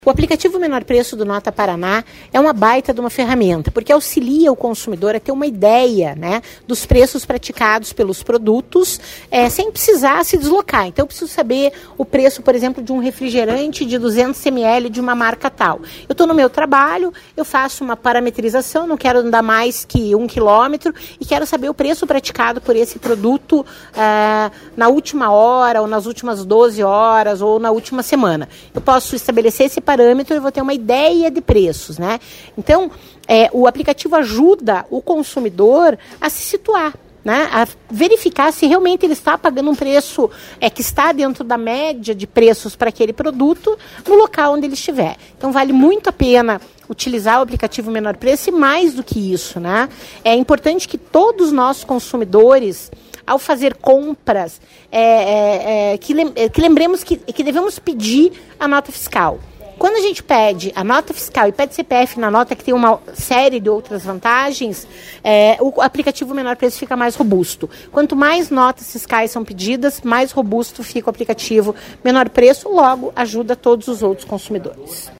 Sonora da coordenadora do Procon, Claudia Silvano, sobre o aplicativo Menor Preço